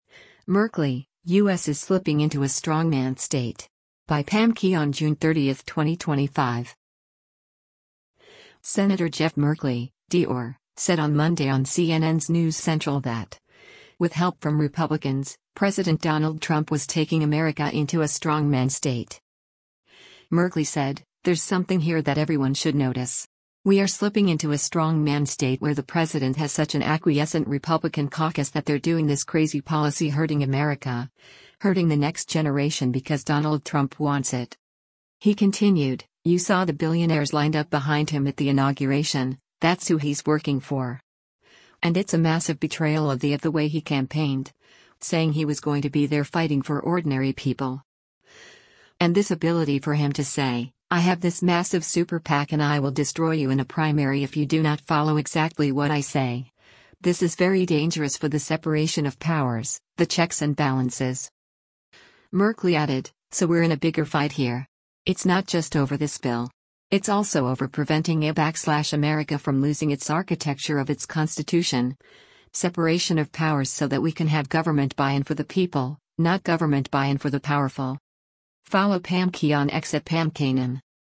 Senator Jeff Merkley (D-OR) said on Monday on CNN’s “News Central” that, with help from Republicans, President Donald Trump was taking America into a “strongman state.”